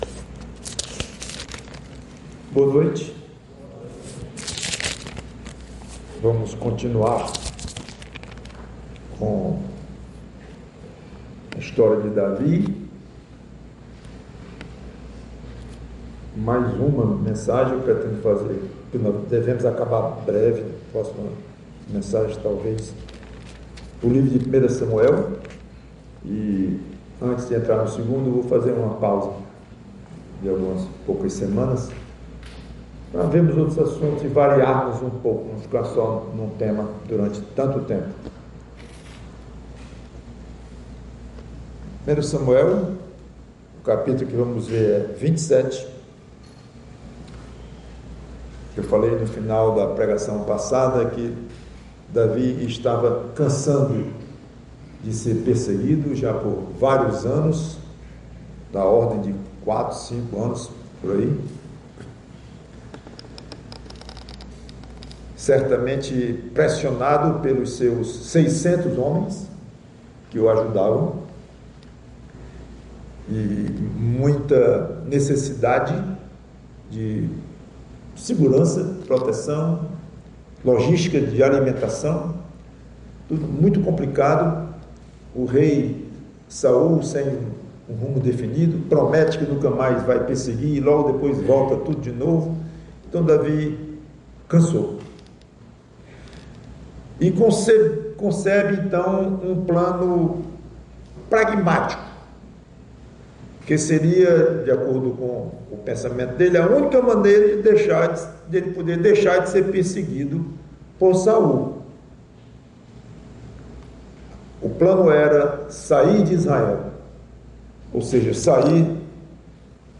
PREGAÇÃO